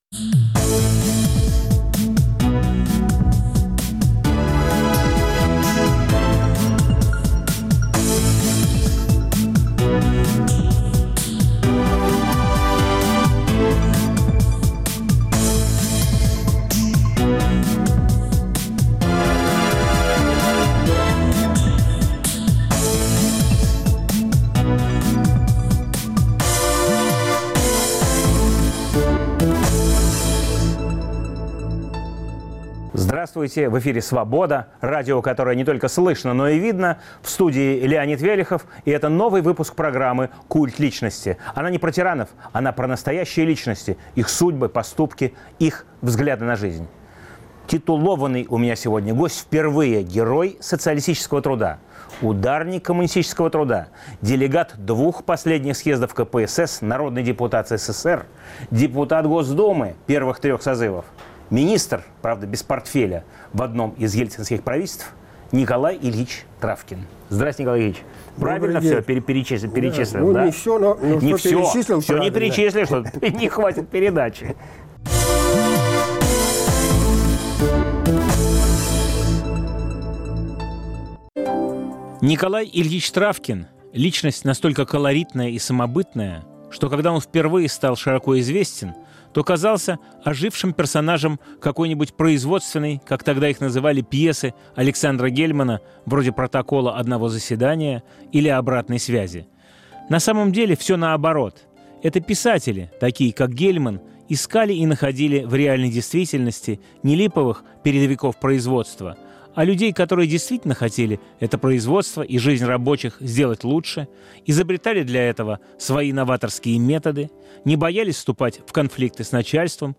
Новый выпуск программы о настоящих личностях, их судьбах, поступках и взглядах на жизнь. В студии Герой Социалистического труда, один из лидеров демократического движения конца 1980-х - начала 90-х Николай Травкин.